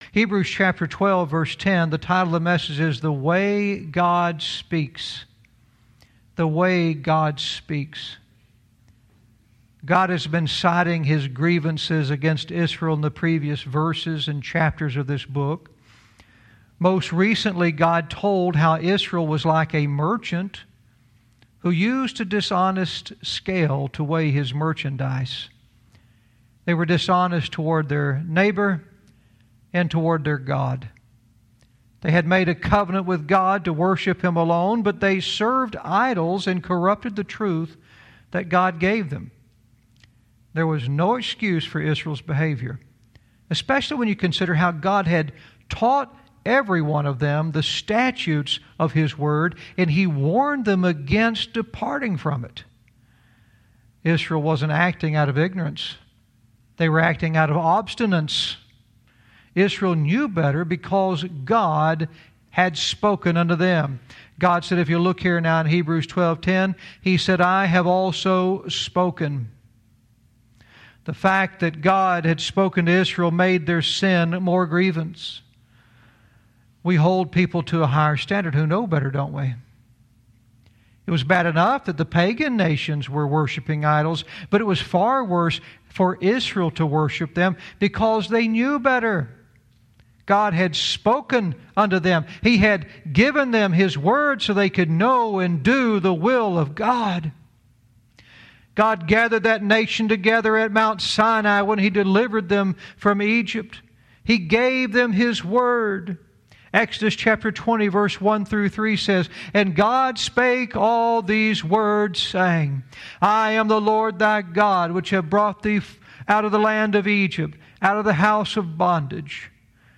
Verse by verse teaching - Hosea 12:10 "The Way God Speaks"